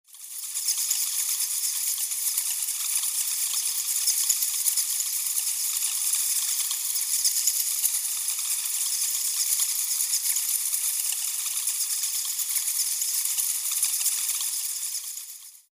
bats-sound